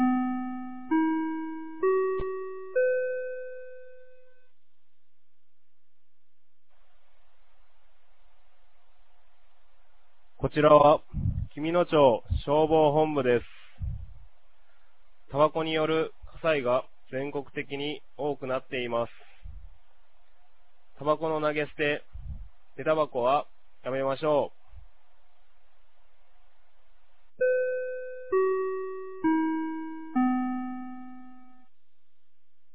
2025年10月18日 16時00分に、紀美野町より全地区へ放送がありました。